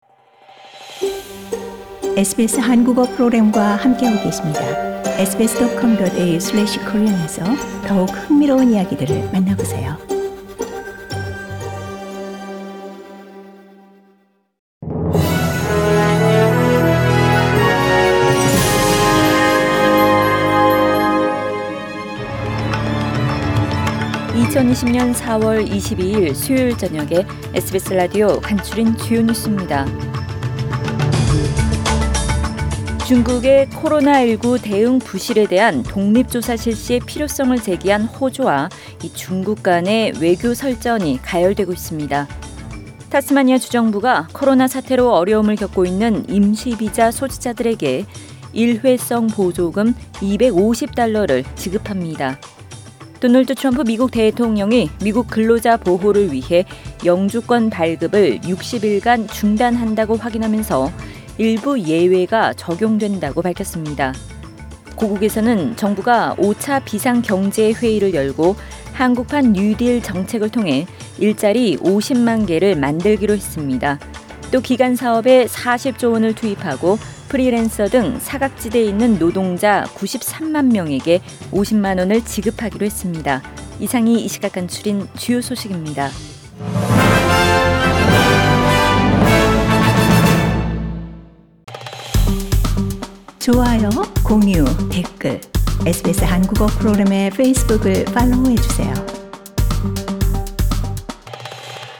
2020년 4월 22일 수요일 저녁의 SBS Radio 한국어 뉴스 간추린 주요 소식을 팟 캐스트를 통해 접하시기 바랍니다.